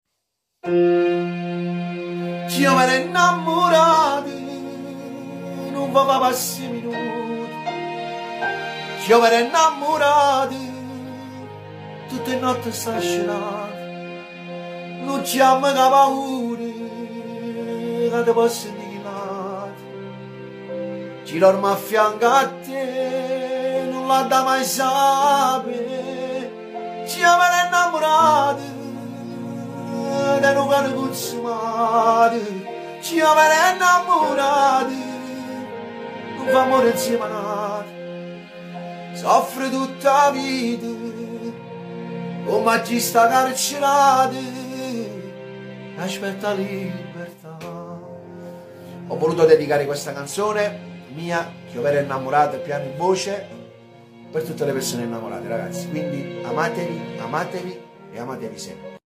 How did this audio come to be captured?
live piano e voce